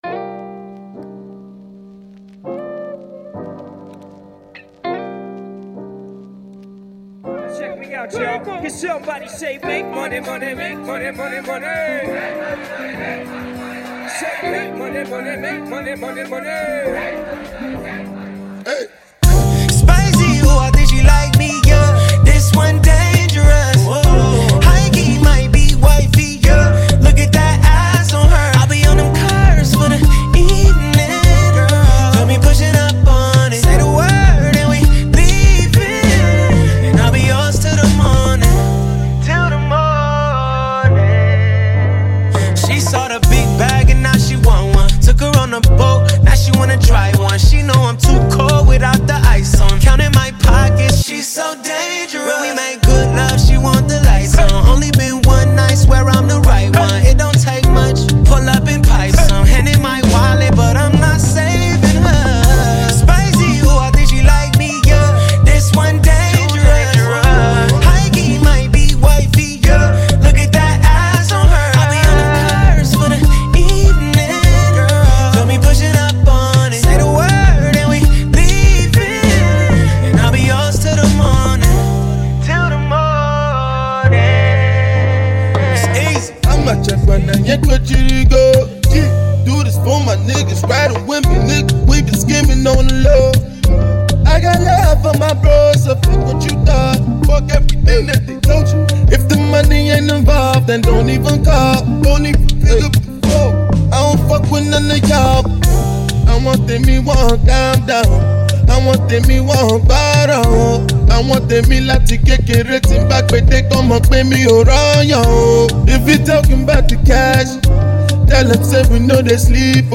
Famous American Singer